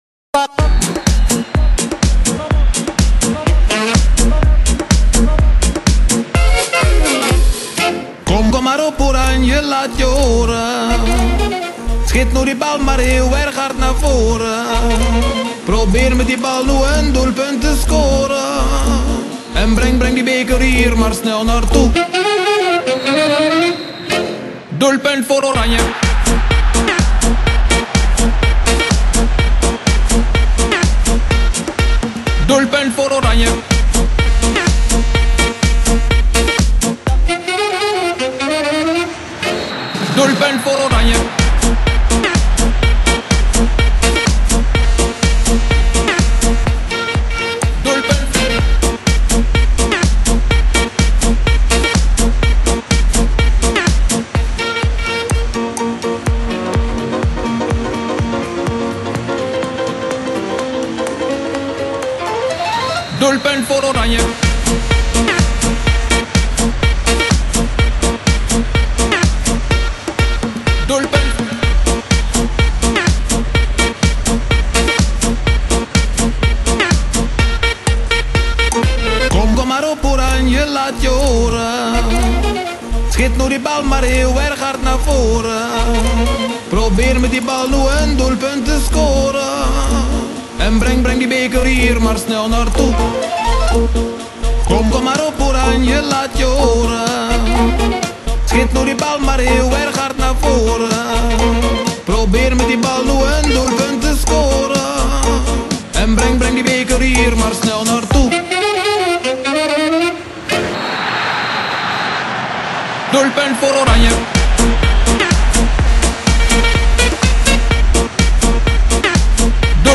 Swing house